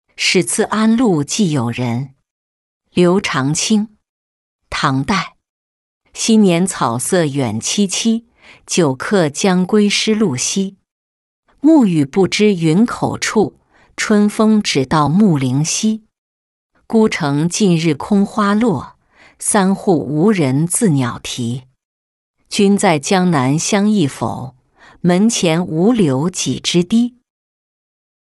使次安陆寄友人-音频朗读